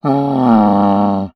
MONSTER_Groan_04_mono.wav